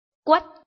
臺灣客語拼音學習網-客語聽讀拼-南四縣腔-入聲韻
拼音查詢：【南四縣腔】guad ~請點選不同聲調拼音聽聽看!(例字漢字部分屬參考性質)